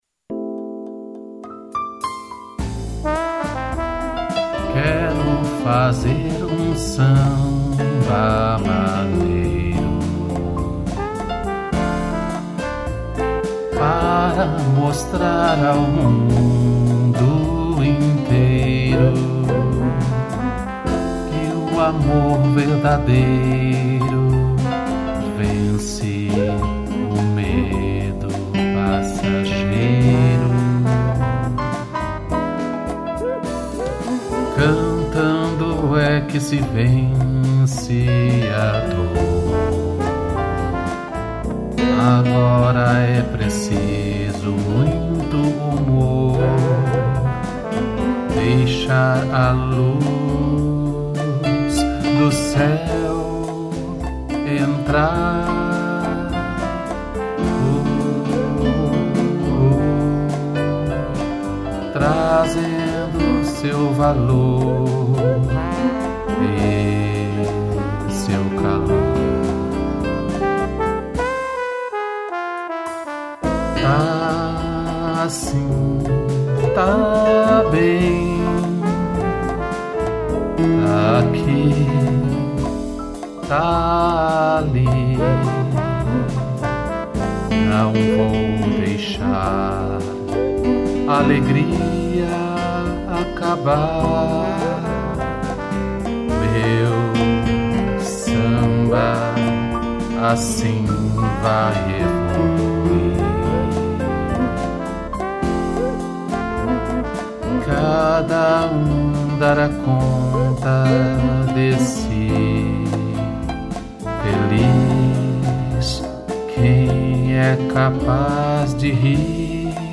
piano, trombone, cuíca e tamborim